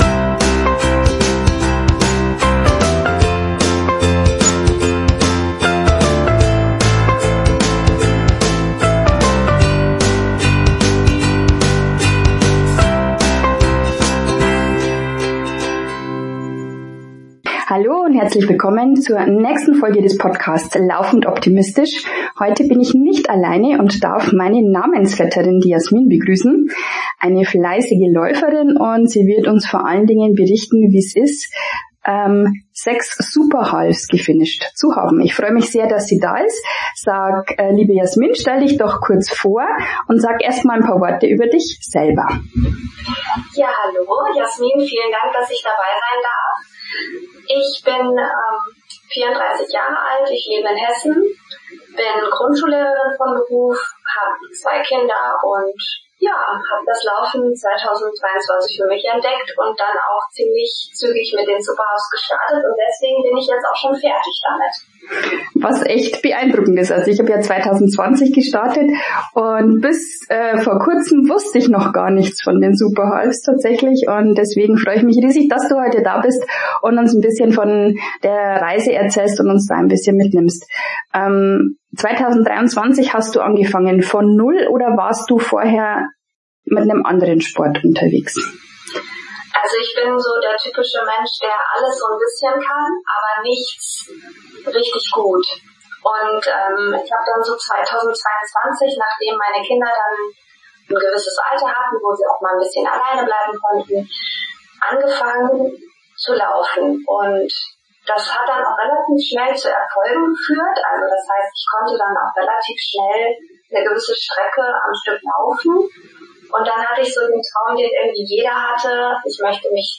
ACHTUNG: Leider hatte ich diesmal vergessen, das Echo bei der Aufnahme auszuschalten. Deswegen ist die Tonqualität nicht so wie sie sein sollte.